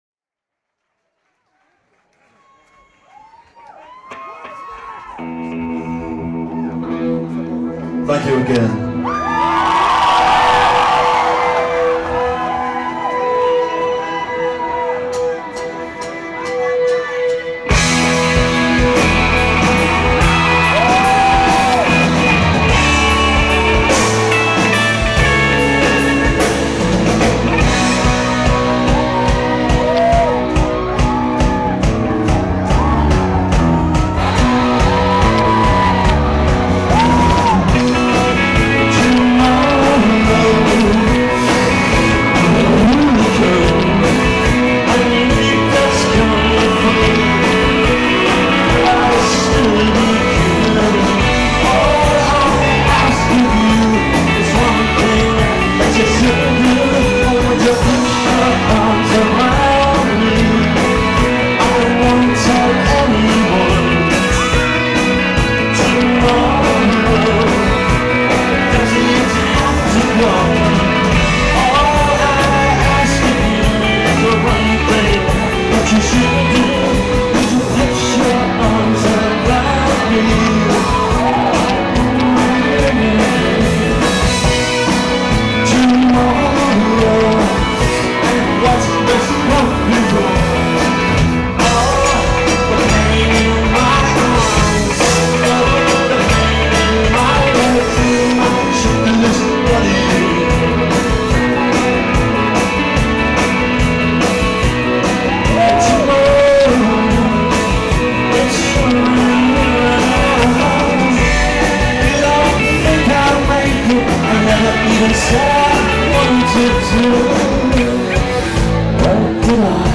しかし、上手く録音できなかった。 割れまくっている。